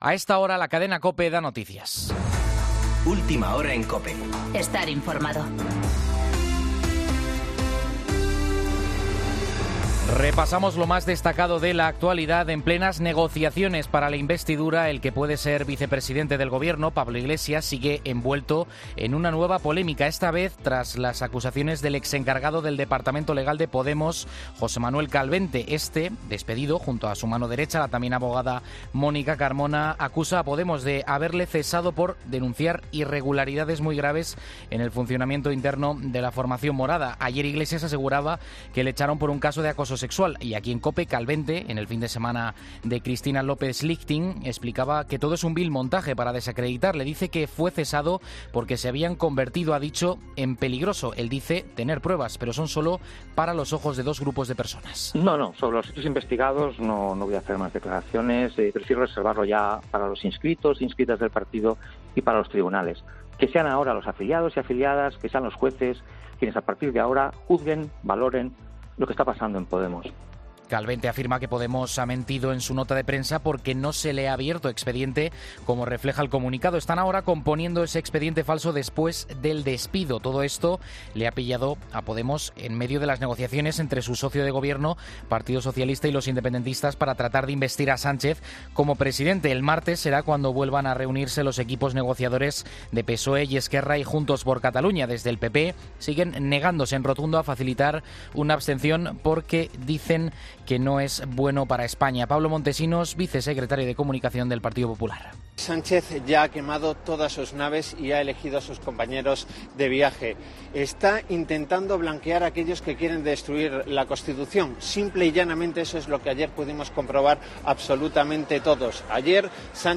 Boletín de noticias COPE del 7 de diciembre de 2019 a las 19.00 horas